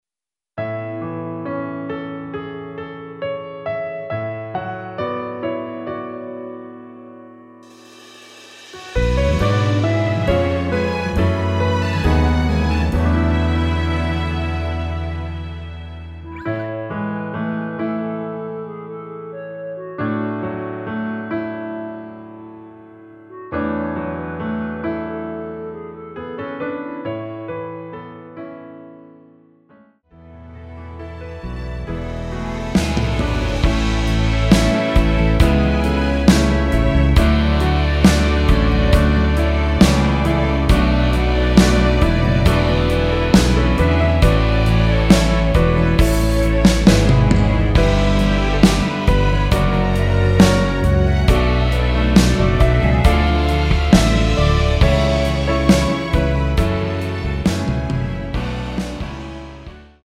(-2)내린 멜로디 포함된 MR 입니다.(미리듣기 참조)
◈ 곡명 옆 (-1)은 반음 내림, (+1)은 반음 올림 입니다.
앞부분30초, 뒷부분30초씩 편집해서 올려 드리고 있습니다.